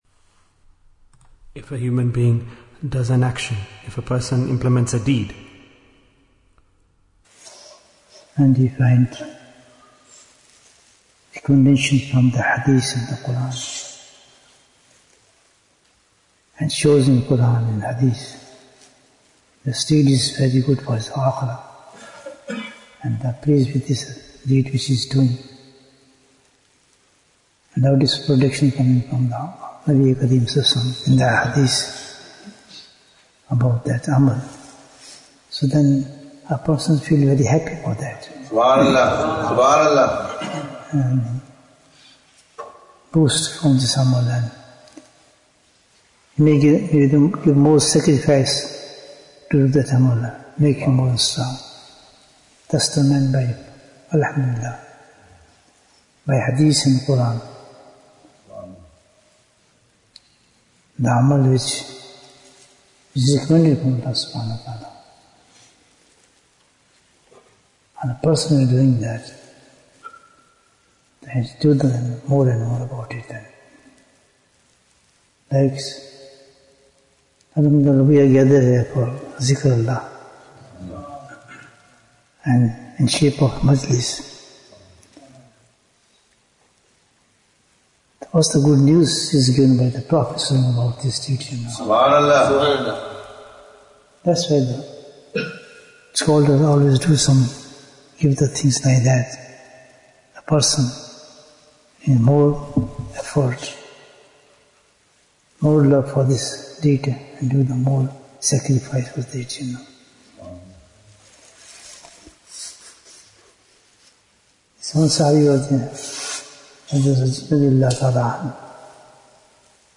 Jewels of Ramadhan 2026 - Episode 24 Bayan, 19 minutes4th March, 2026